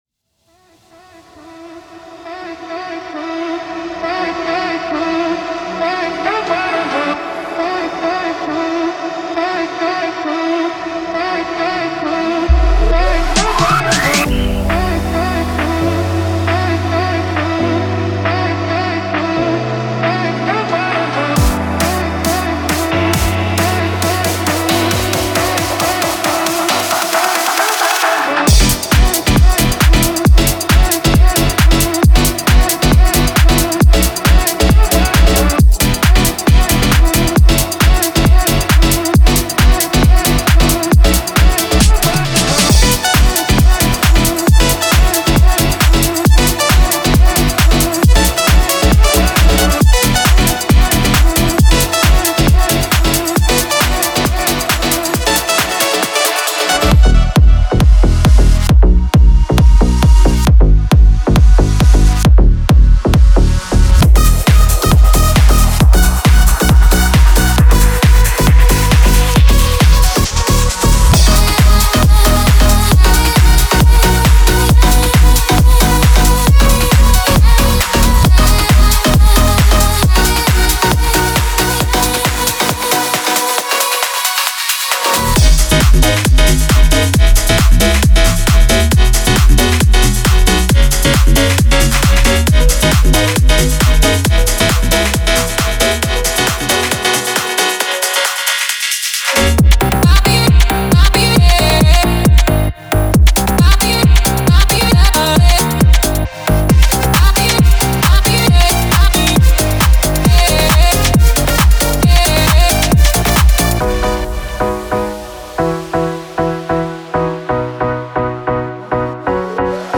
Genre:House
135BPMで構成された185種類のループとワンショットを収録しています。
デモサウンドはコチラ↓